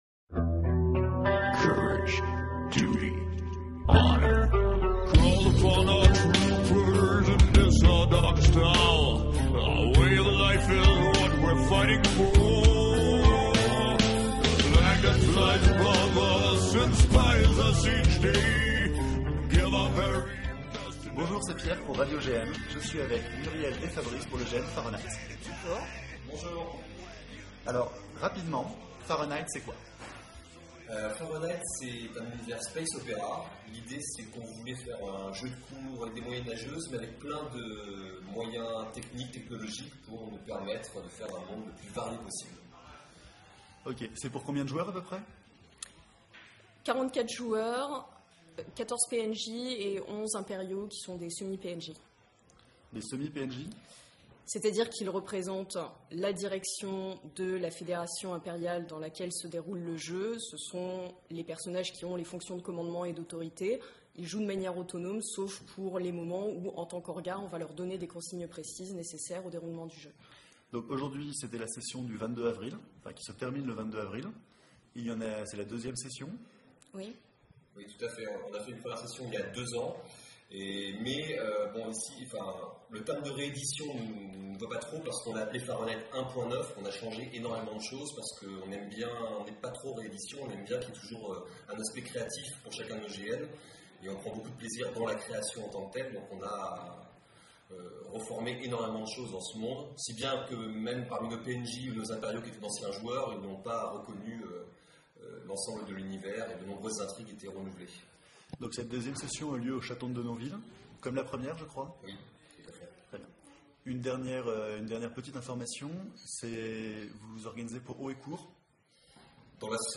Des émissions de 15 à 20 minutes enregistrées sur le vif pour vous présenter un GN, avec des interviews à chaud des organisateurs et des joueurs. Dans ce premier épisode de Radio-GN, découvrez Farenheit, un GN de l’association Haut et Court.